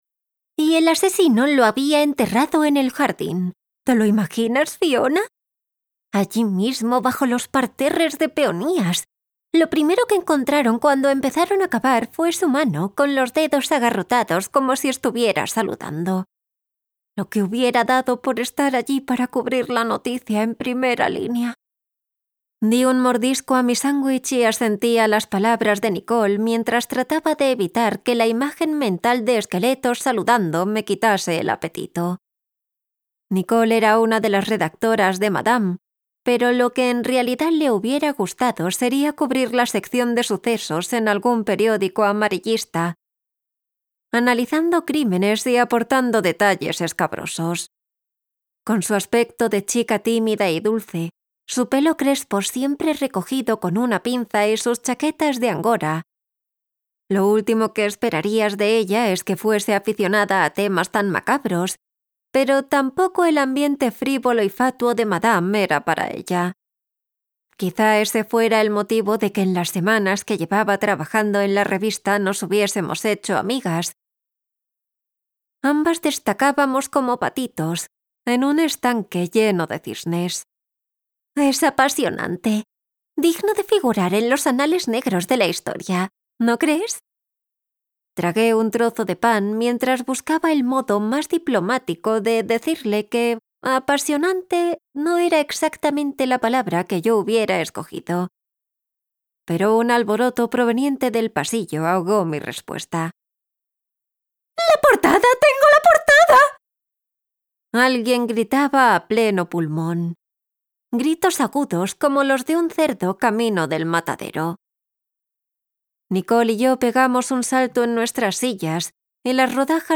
Audiolibro Un plan imperfecto (An Imperfect Plan)